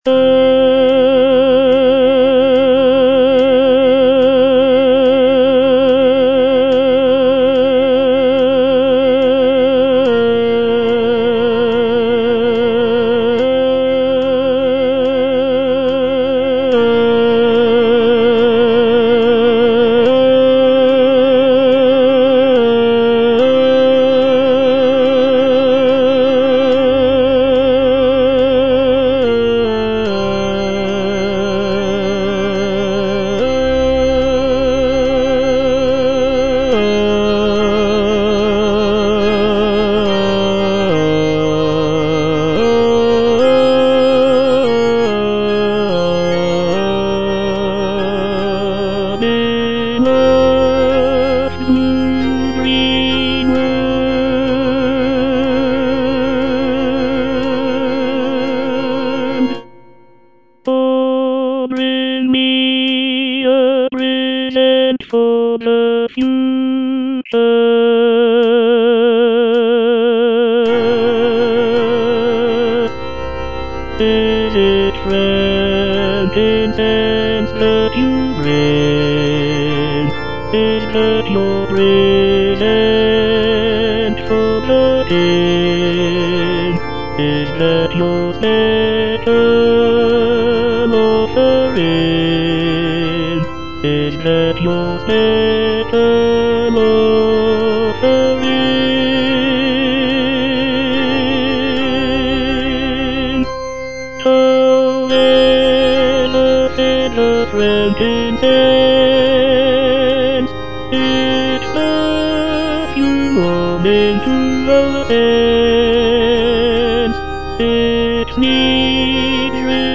Tenor (Voice with metronome)
is a choral work